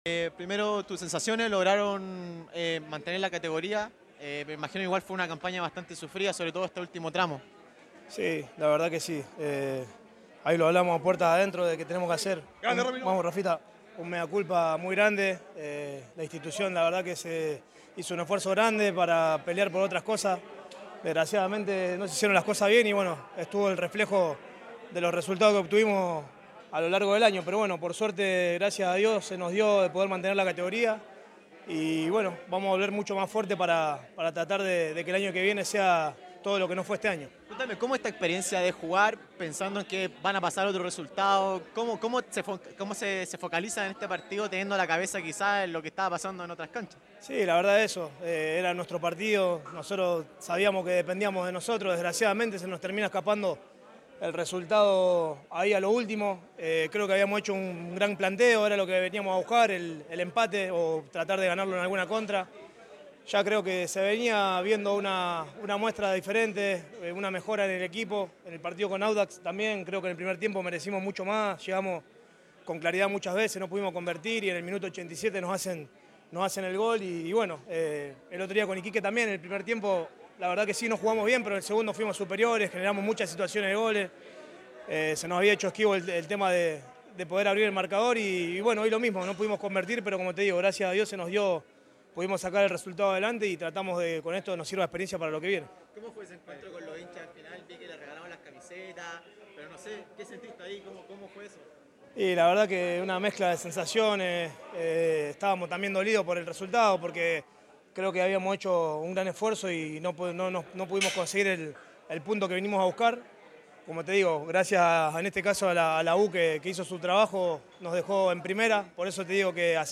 En conversación con ADN Deportes